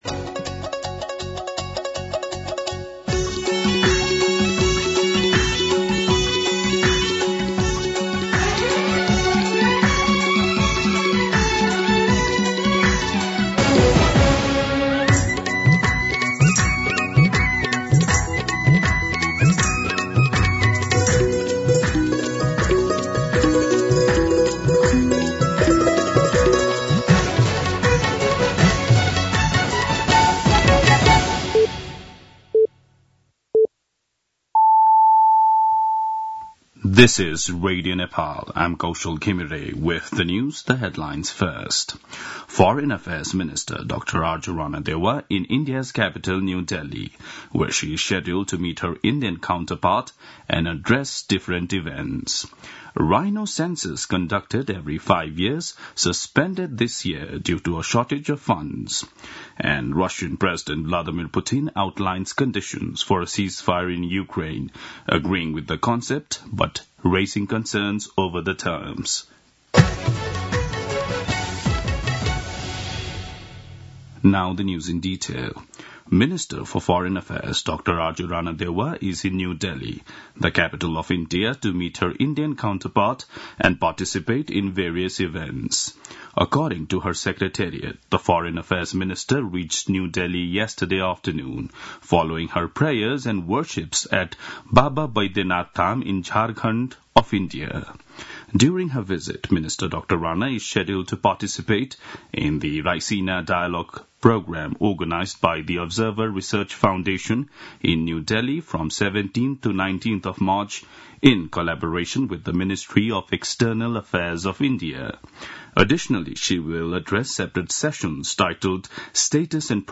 दिउँसो २ बजेको अङ्ग्रेजी समाचार : १ चैत , २०८१
2pm-English-News-01.mp3